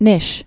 (nĭsh)